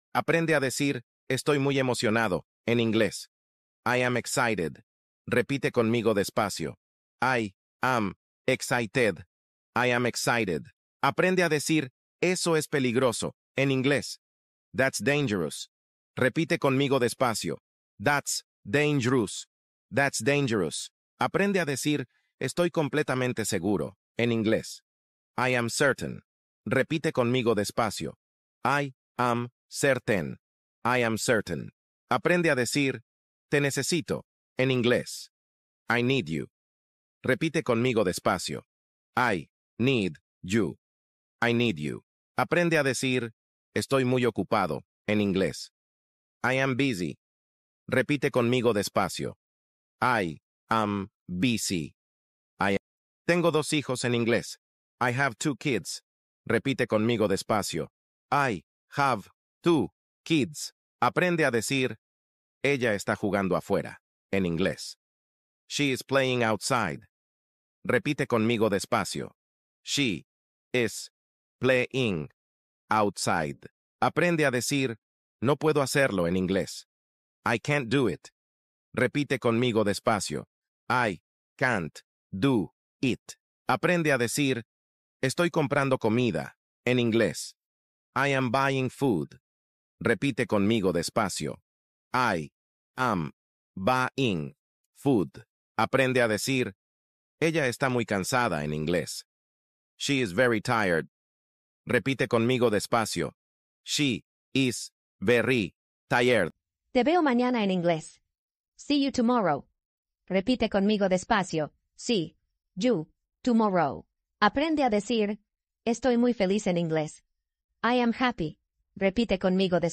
Práctica de inglés para super principiantes: fácil, lento y claro ✅